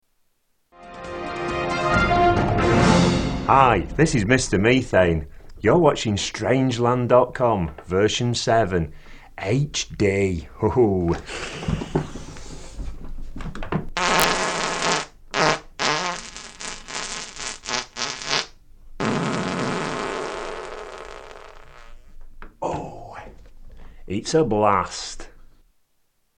Farting Newscaster
Tags: Comedians Mr Methane Fart Fart Music Paul Oldfield